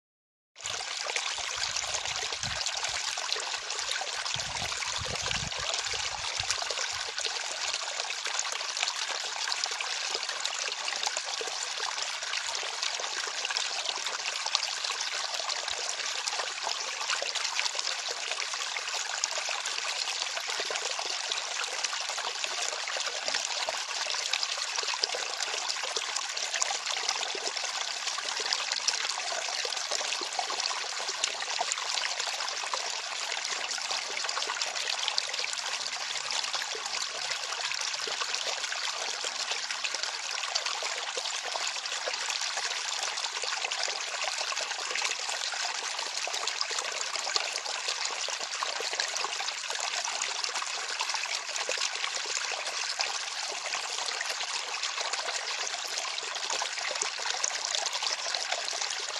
Water flowing sound
It's almost spring and the snow is melting, so I thought I should record some water flowing sounds.There is a little bit of extra noise in the beginning, so if you are going to use it somewhere I recommend cutting it out.